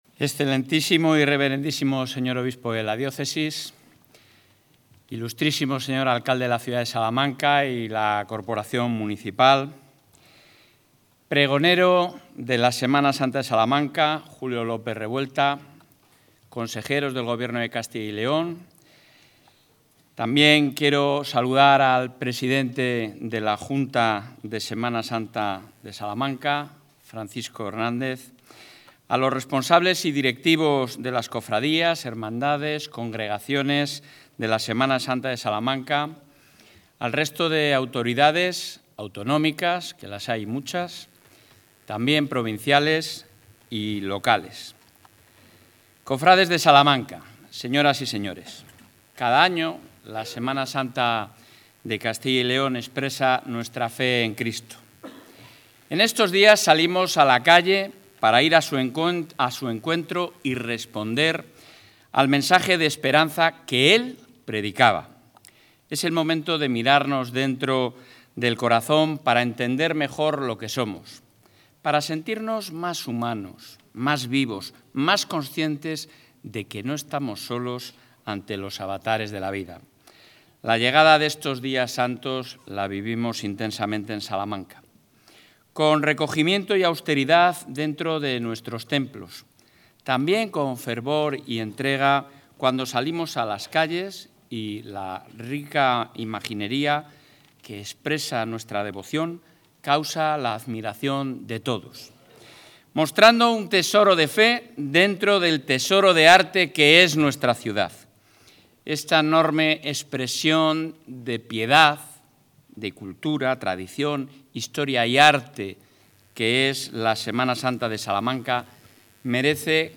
El presidente de la Junta de Castilla y León asiste al Pregón de la Semana Santa de Salamanca
Intervención del presidente.